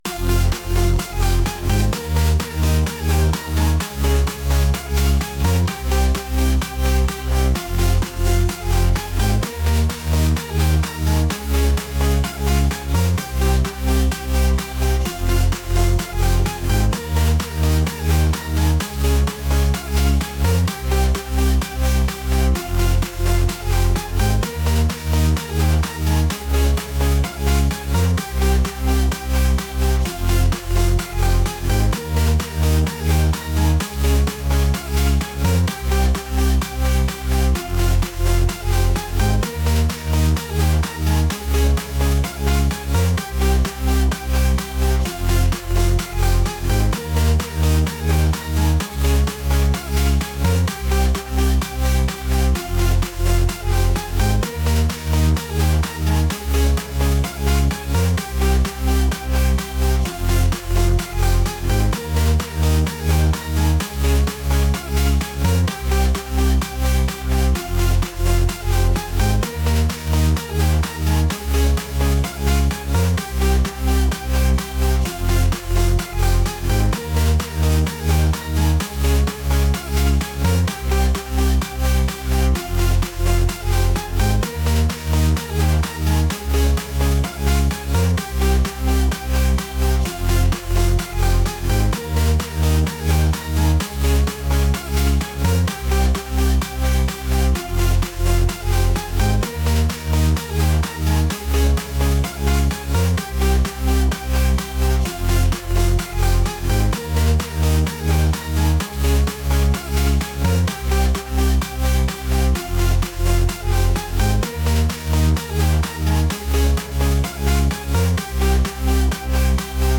upbeat | energetic